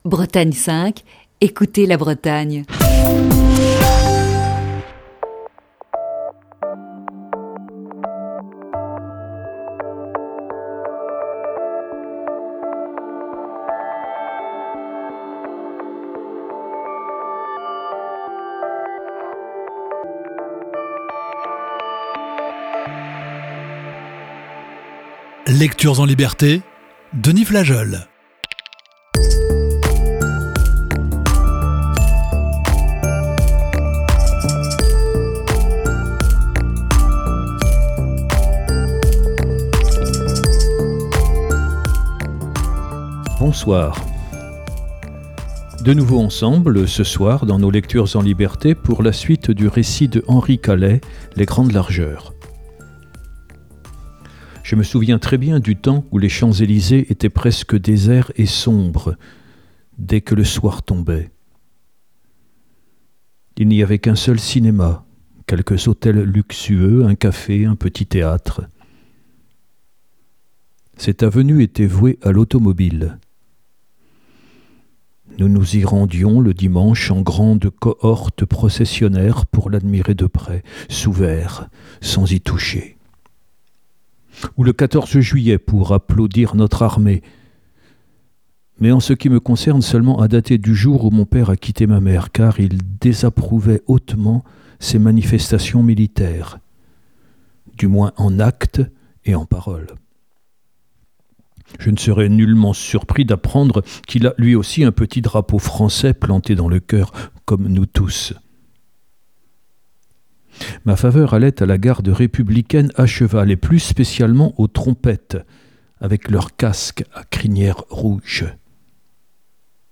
Voici ce soir la troisième partie de ce récit.